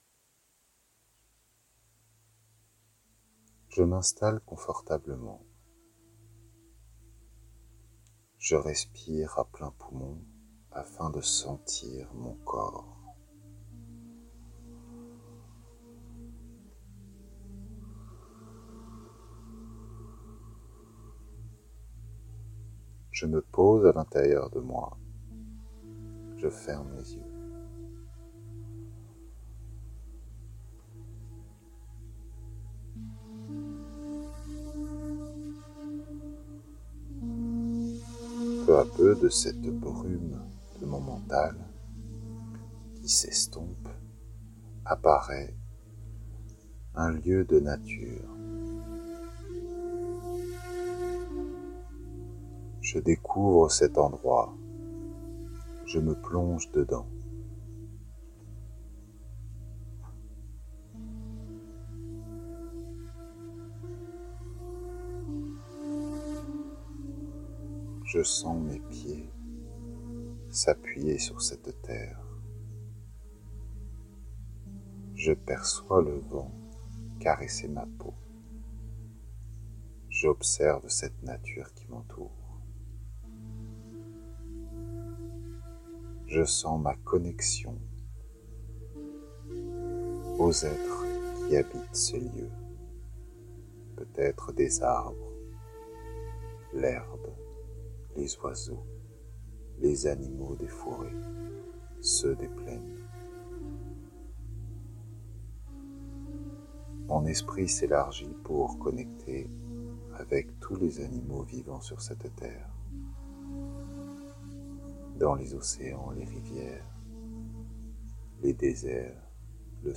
Pour ceux et celles qui en auraient besoin je vous propose une petite méditation de 7 minutes (